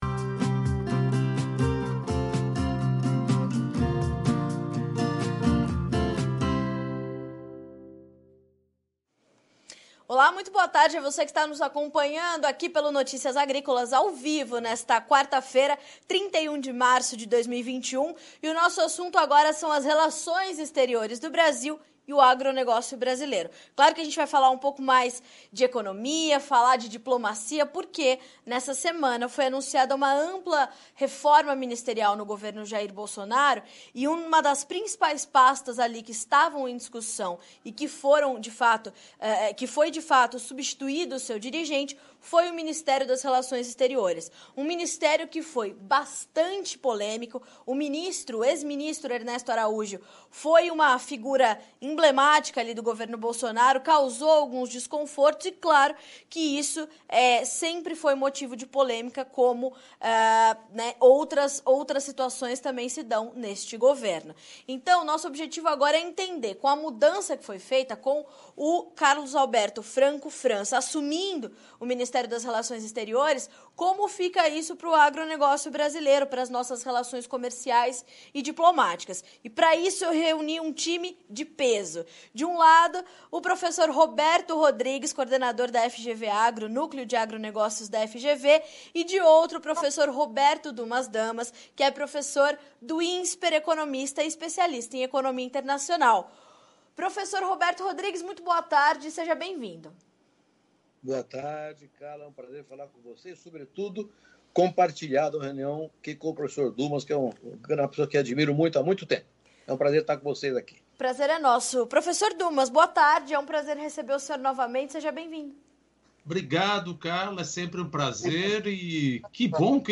Especialistas debatem impactos da mudança na pasta e a importância disso para um setor estratégico como a produção de alimentos para o Brasil.
Entrevista